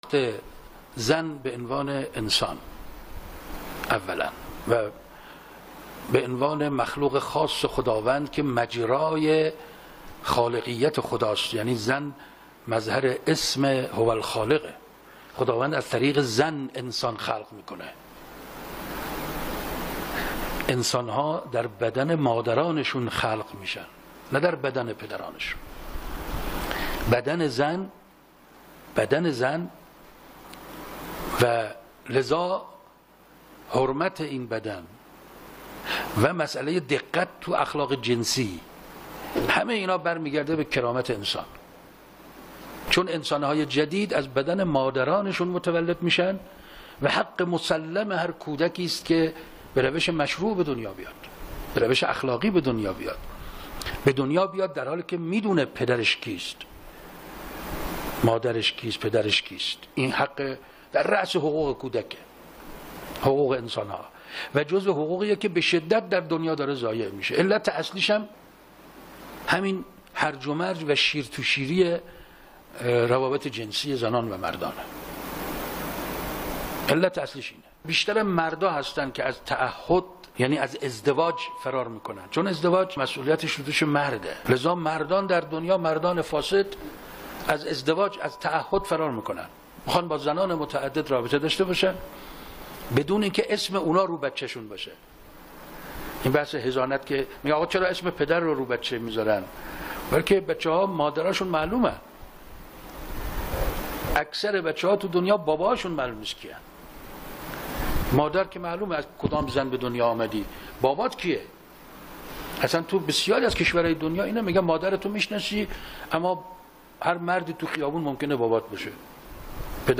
سخنران: استاد رحیم پور ازغدی نگاهی به جایگاه و شأن‌ و‌ کرامت زن، از نگاه اسلام